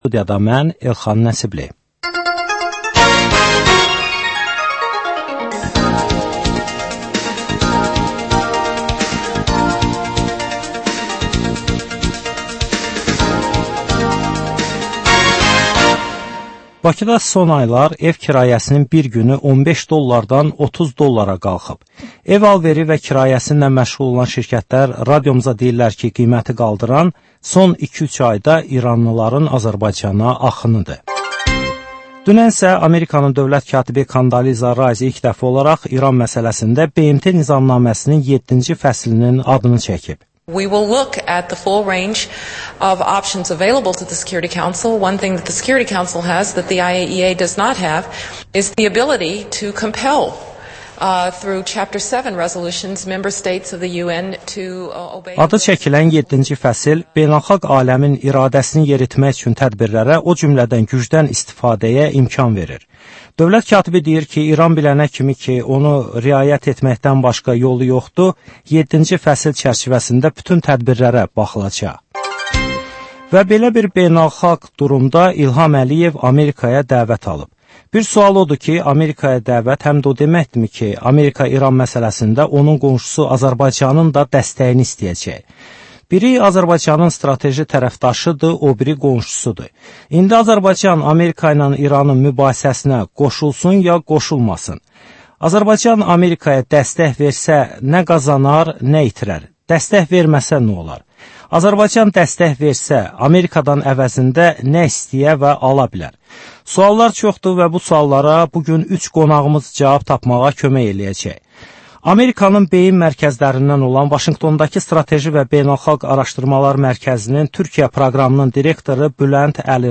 Dəyirmi masa söhbəti.